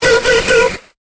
Cri de Démanta dans Pokémon Épée et Bouclier.